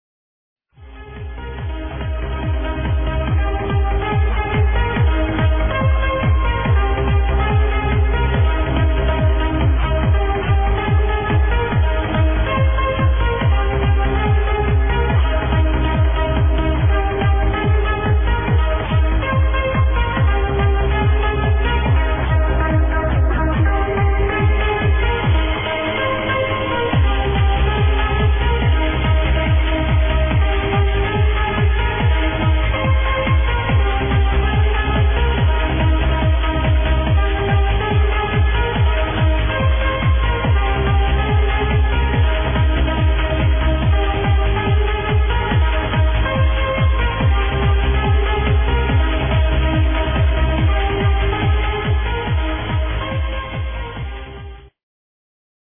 great uplifting tune!
i heard this song only in this mix....